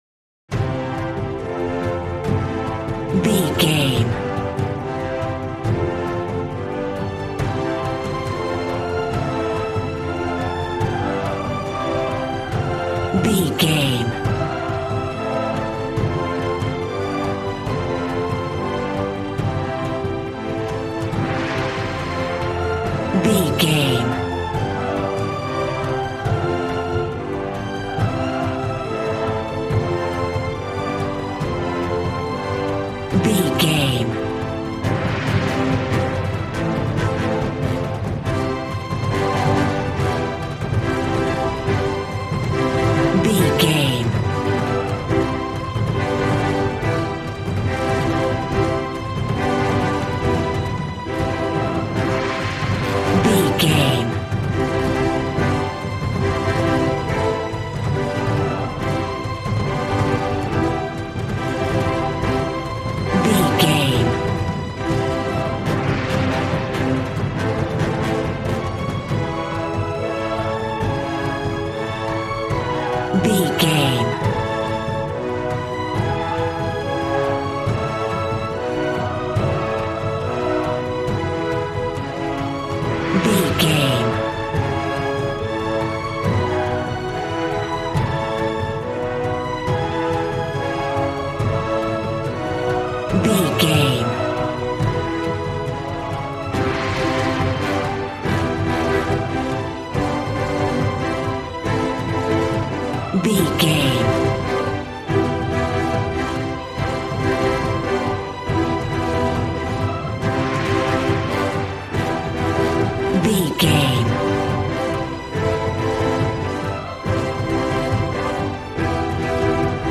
Action and Fantasy music for an epic dramatic world!
Aeolian/Minor
groovy
drums
bass guitar
electric guitar